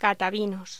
Locución: Catavinos
voz